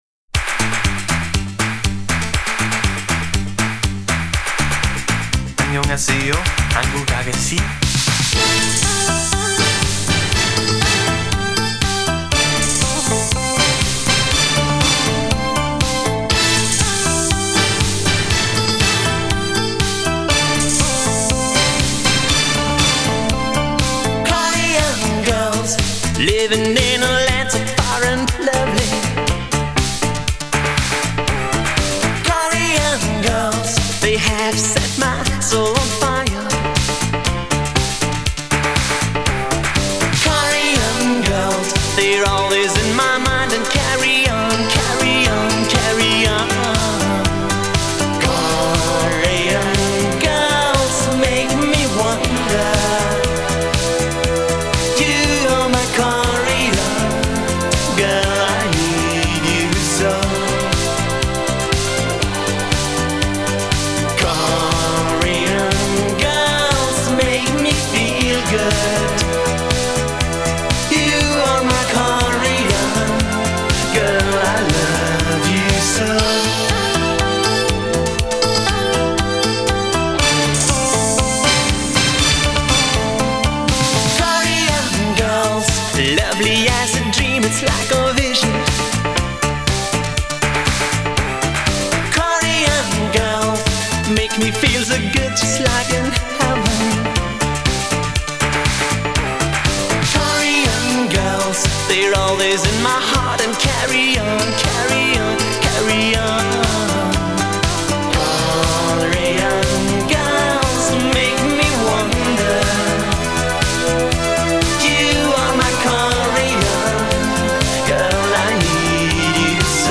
Disco Italo Disco